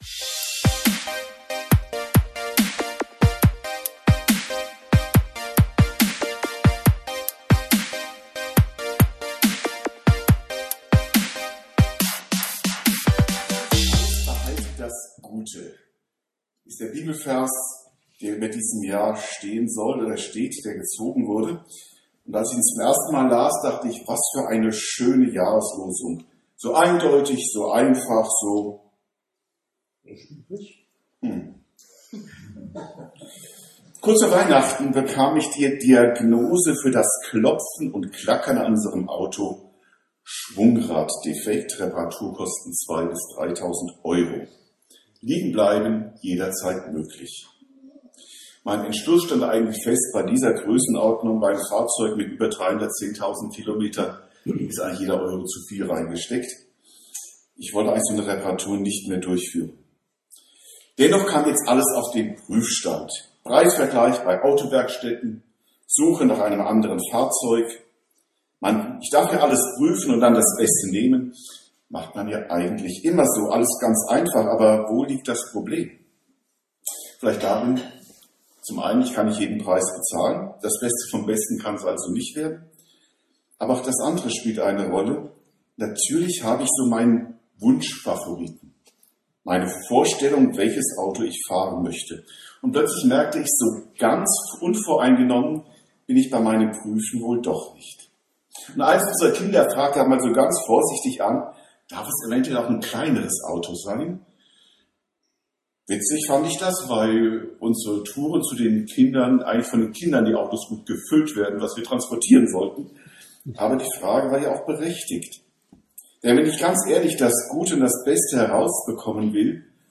Prüft alles - behaltet das Gute ~ Predigten u. Andachten (Live und Studioaufnahmen ERF) Podcast